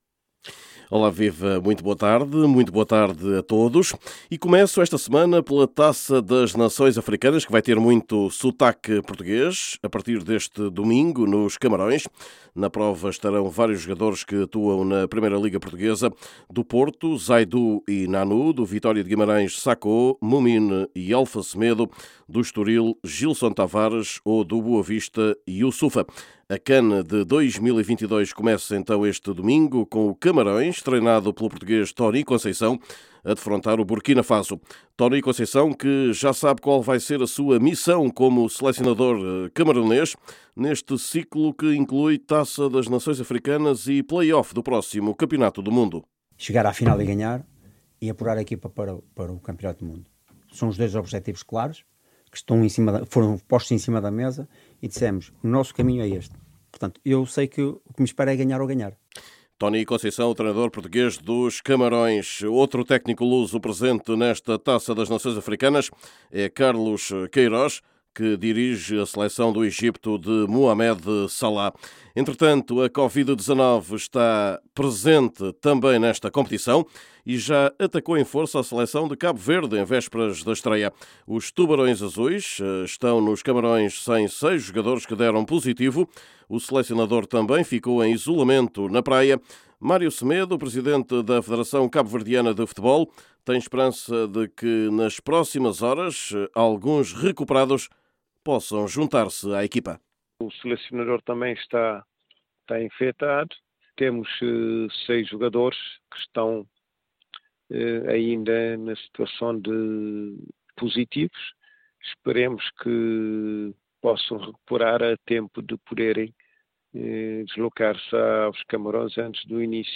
Neste boletim esportivo, também o facto de Ronaldo ter ficado de fora dos finalistas para o prémio “The Best”, da FIFA, de 2021.